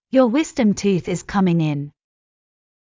ﾕｱ ｳｨｽﾞﾀﾞﾑ ﾄｩｰｽ ｲｽﾞ ｶﾐﾝｸﾞ ｲﾝ